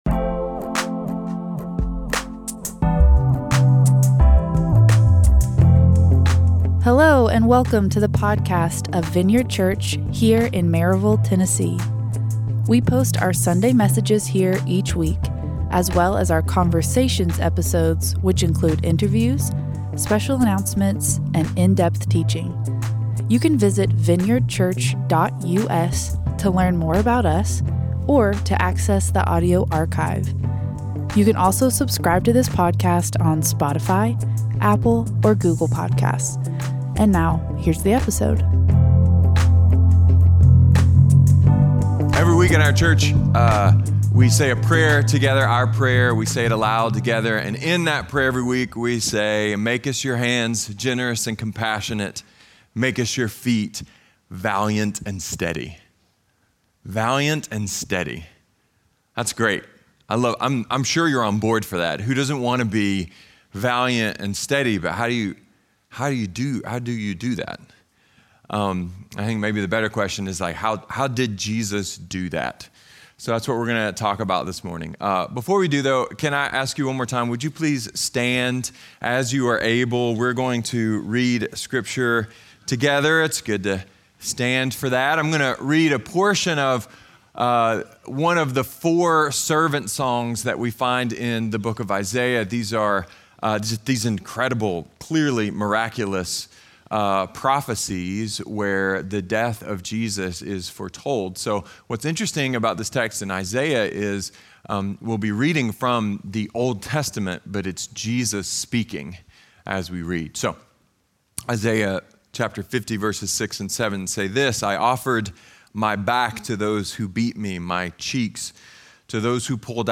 A message from the series "Valiant and Steady."